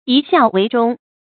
移孝为忠 yí xiào wéi zhōng
移孝为忠发音
成语注音ㄧˊ ㄒㄧㄠˋ ㄨㄟˊ ㄓㄨㄙ